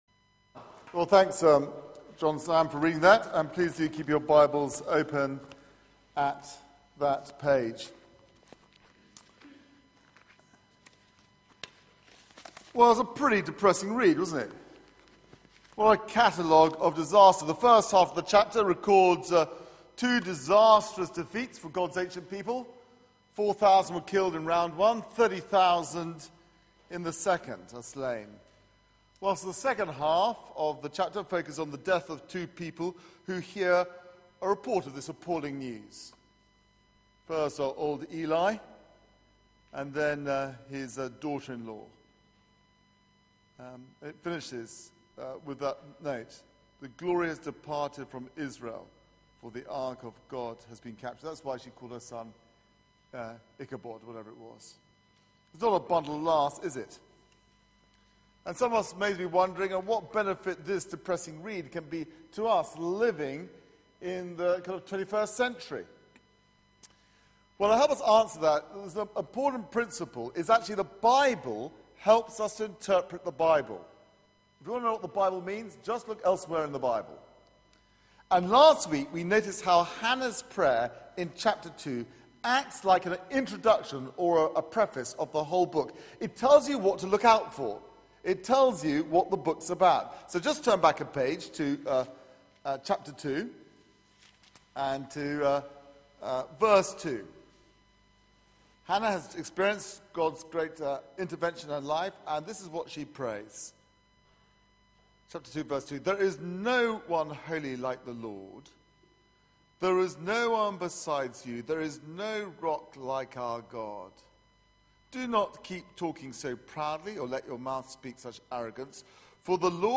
Media for 6:30pm Service on Sun 25th May 2014
Theme: The lost ark Sermon